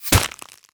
bullet_impact_ice_02.wav